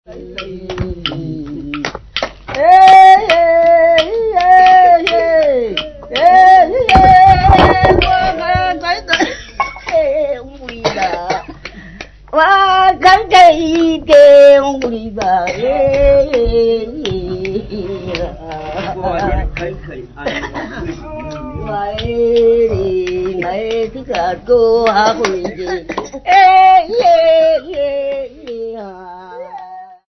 Choral music
Field recordings
Africa Namibia Okombahe f-sx
sound recording-musical
Indigenous music.